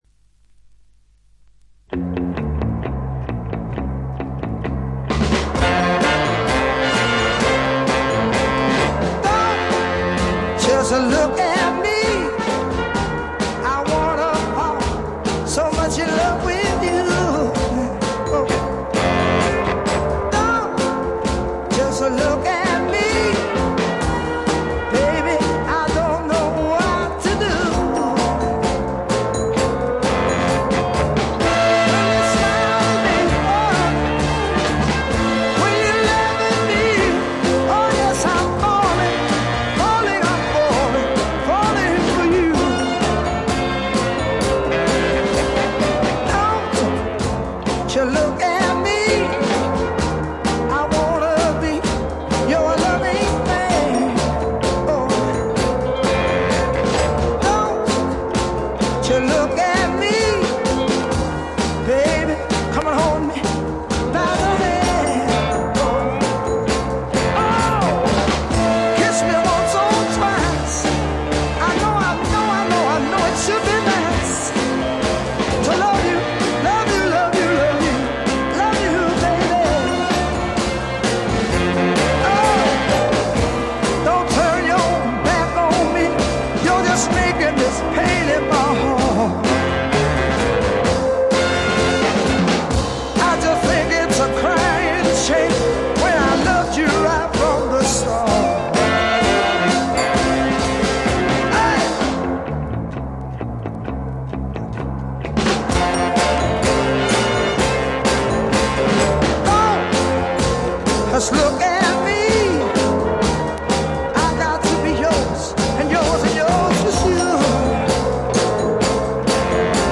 ところどころでチリプチ、バックグラウンドノイズ。特に気になるような大きなノイズはありません。
試聴曲は現品からの取り込み音源です。